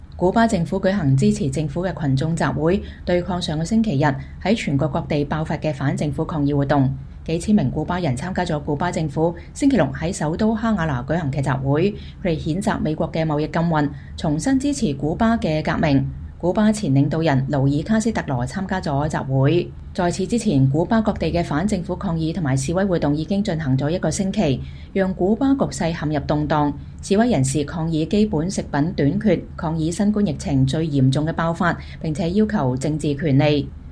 古巴政府在首都哈瓦那舉行支持政府的群眾集會（2021年7月17日）